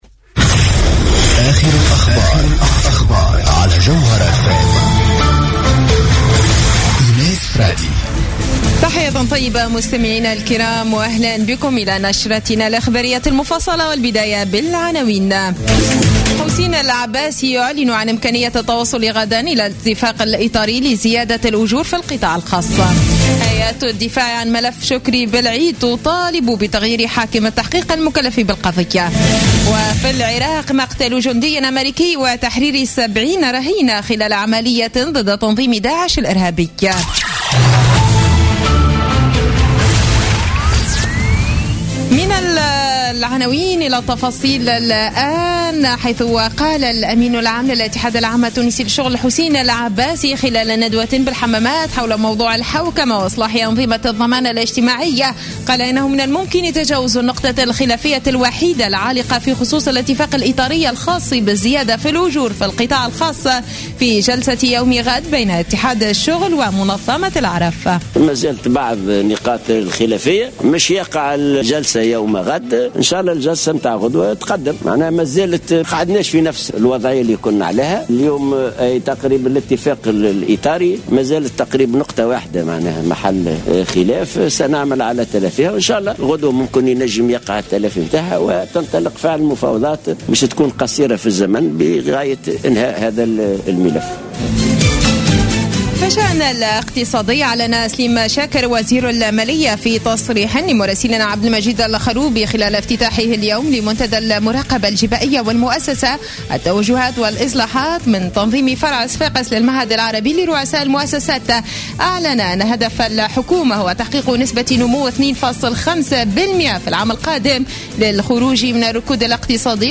نشرة أخبار السابعة مساء ليوم الخميس 22 أكتوبر 2015